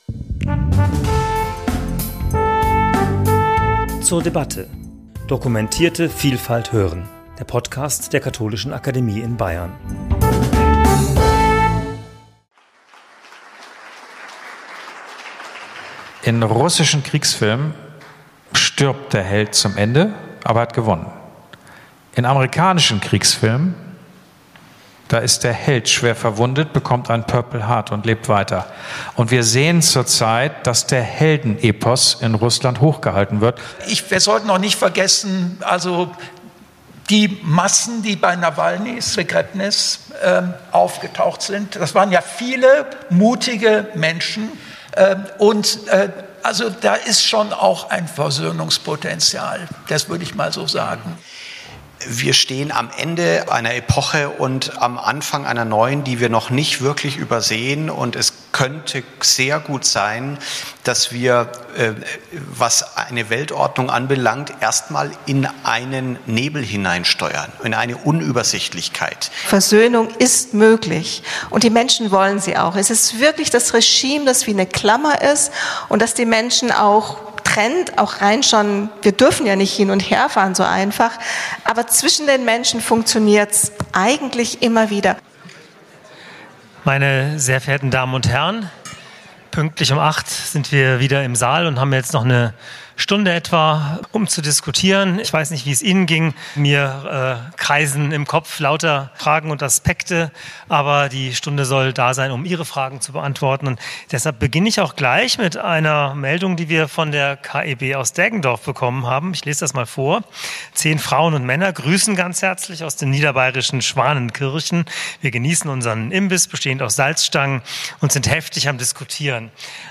Gespräch zum Thema 'Menschenrechte verteidigen - Auf der Suche nach einer gemeinsamen Strategie' ~ zur debatte Podcast